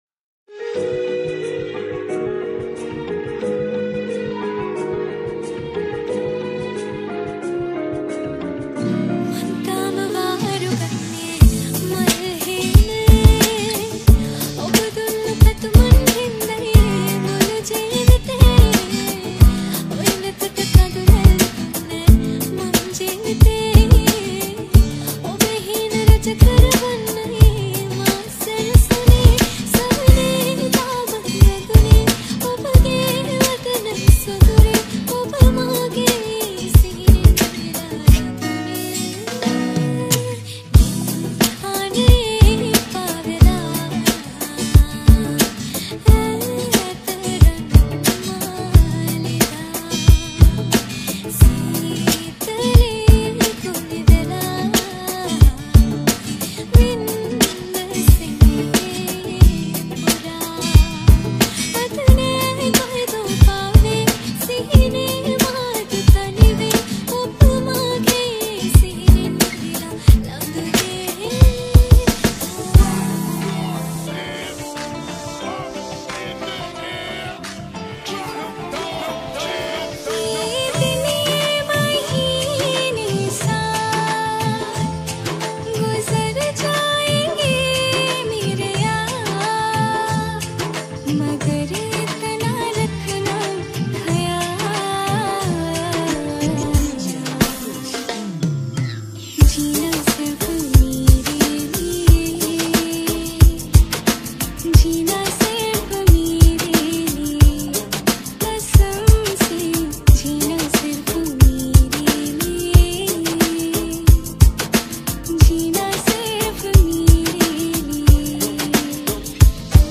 Reggae Remix